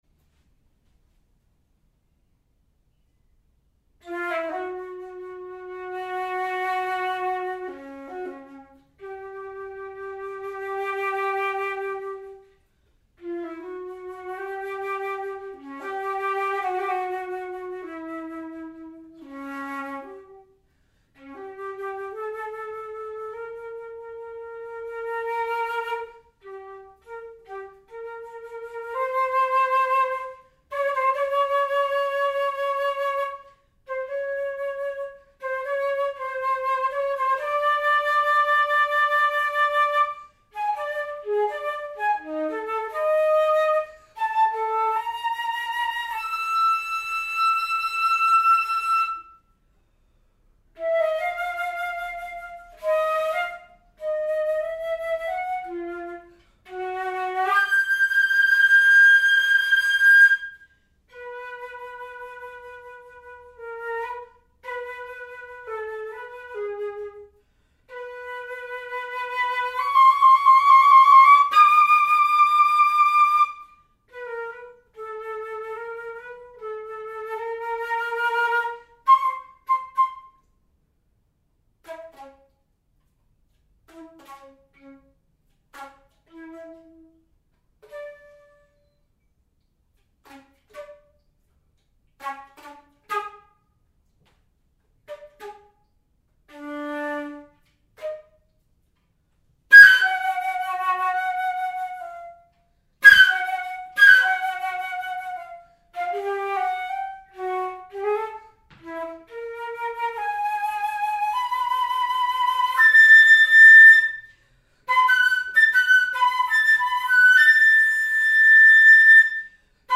for solo flute
using a Zoom Handy6 recording device, Stellenbosch, 2020.
unedited